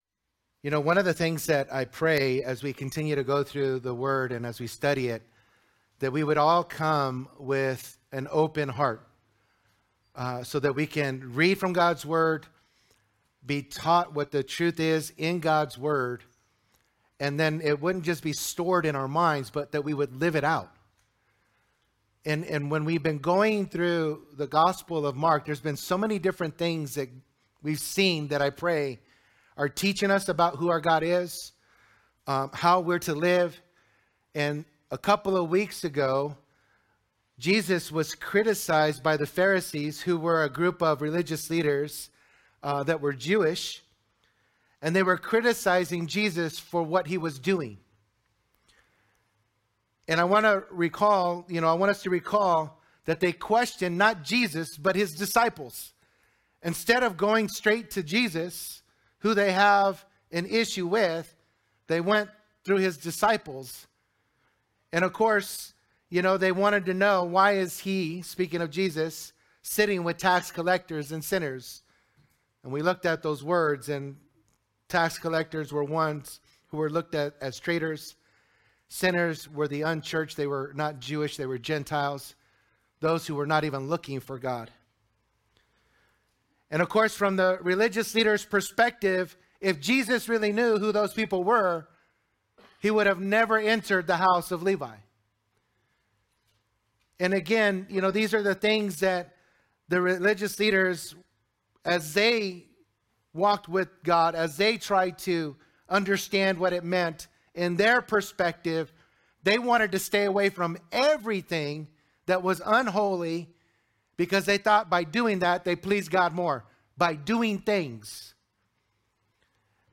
Calvary Chapel Saint George - Sermon Archive